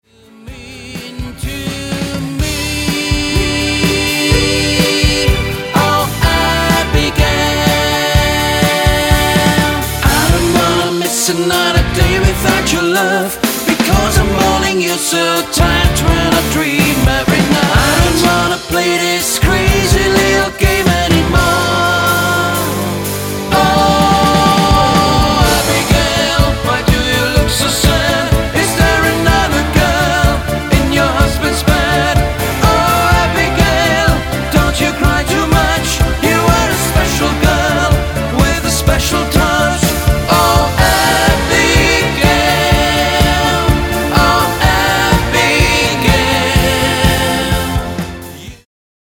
lead & rhythm guitars
drums & percussion